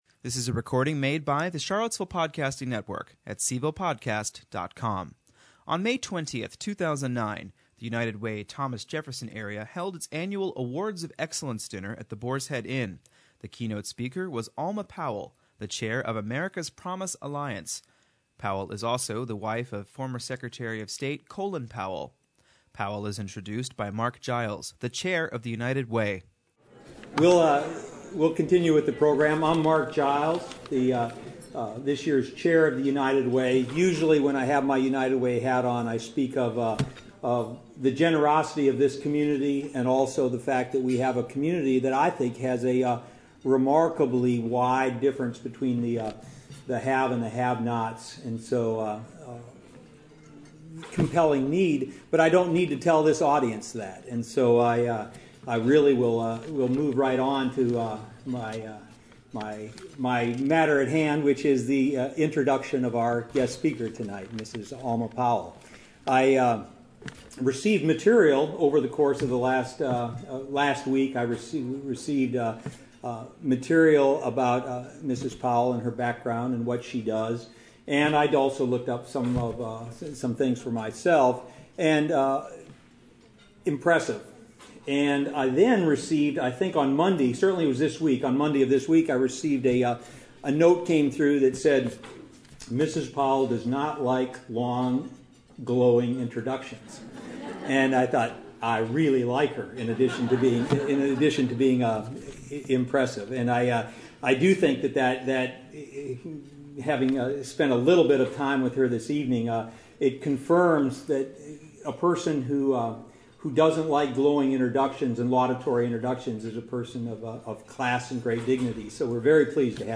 On May 20, 2009, the United Way Thomas Jefferson Area held its annual Awards of Excellence dinner at the Boar’s Head Inn. The keynote speaker was Alma Powell, the chair of America’s Promise Alliance.